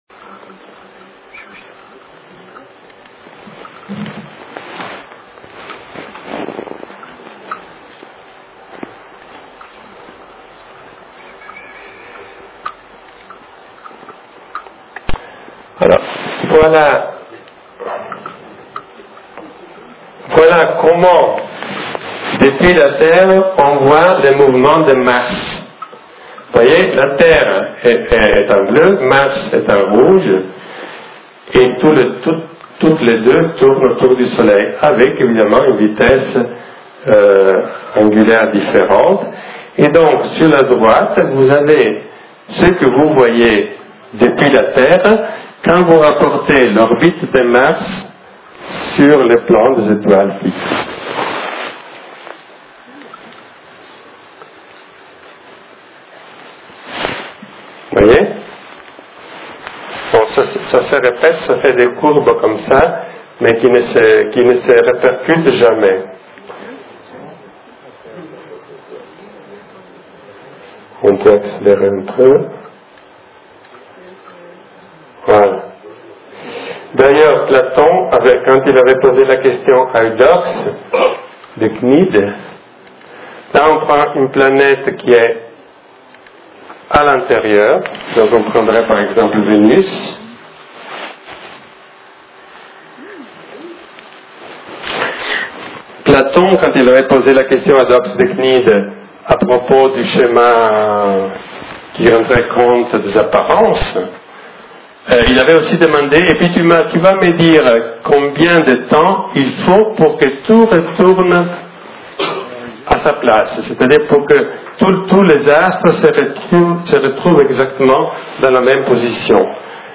Conférence tenue à Cassis le 6.12.2005